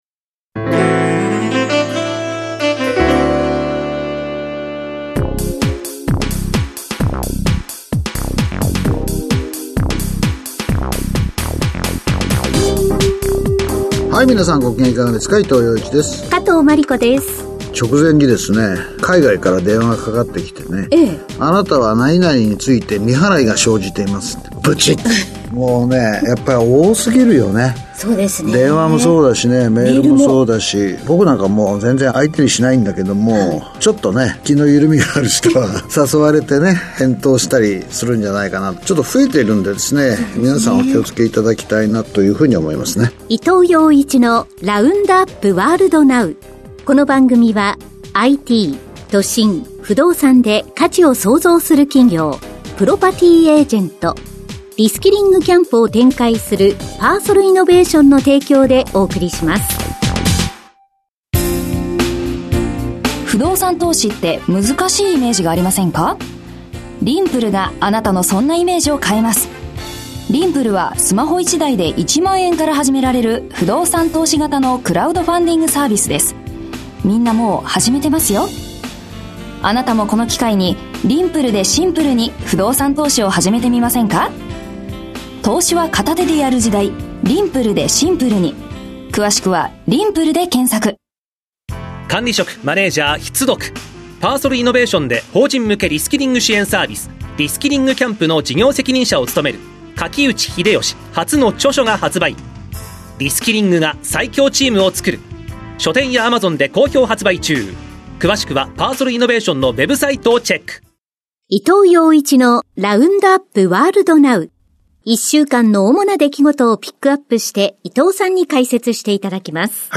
… continue reading 479 эпизодов # ニューストーク # ニュース # ビジネスニュース # NIKKEI RADIO BROADCASTING CORPORATION